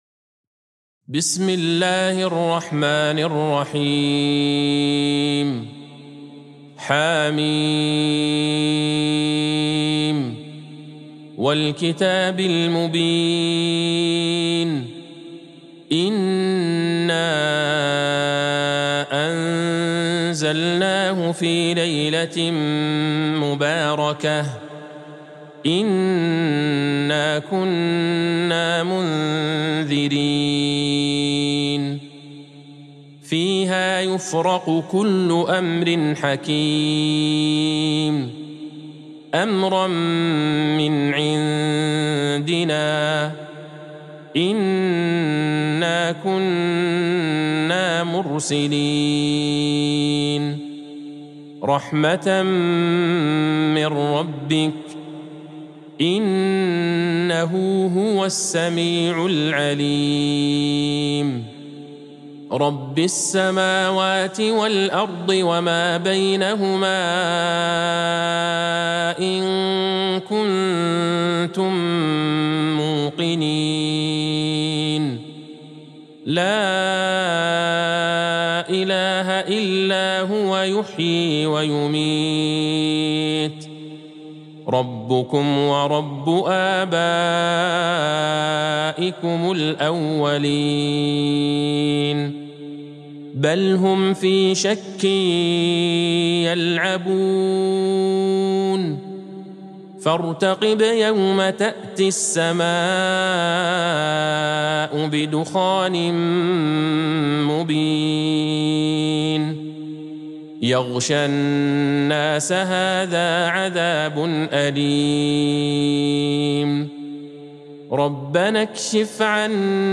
سورة الدخان Surat Ad-Dukhan | مصحف المقارئ القرآنية > الختمة المرتلة